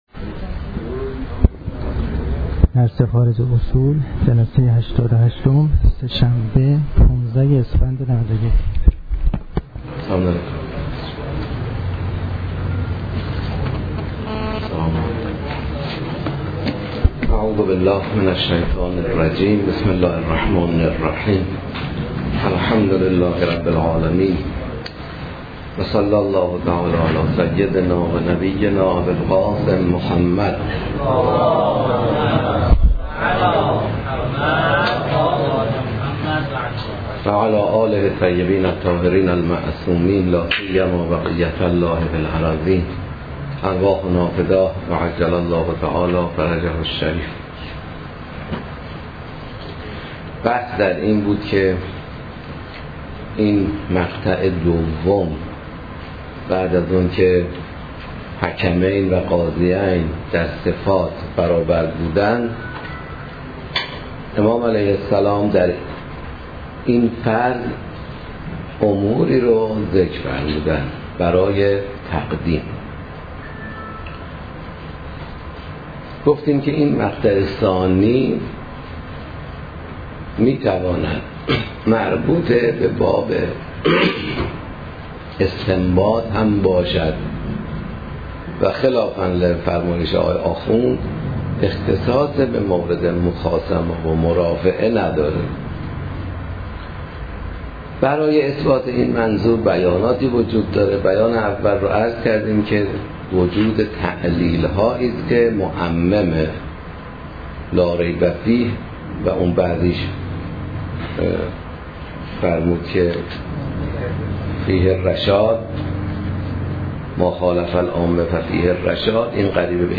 پخش آنلاین درس